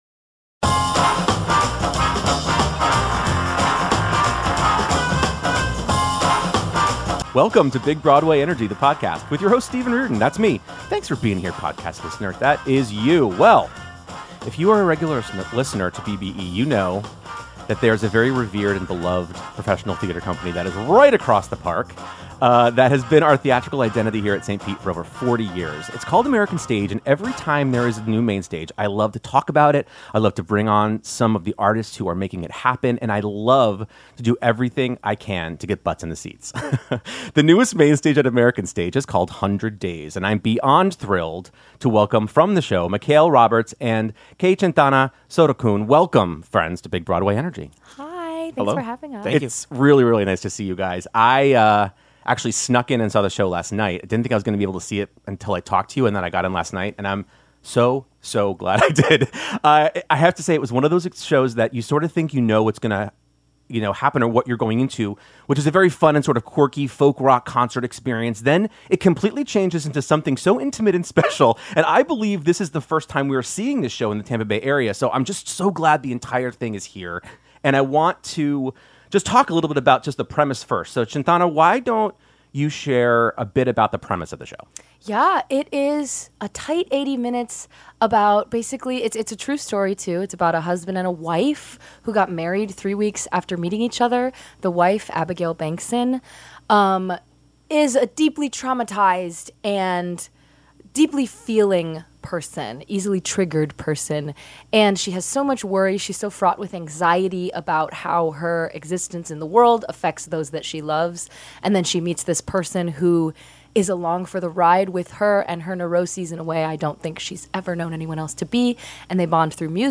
From reflections on previous American Stage productions like Ring of Fire and Hedwig and the Angry Inch to powerful discussions about art, community, and vulnerability, this conversation celebrates what it means to be truly human in performance.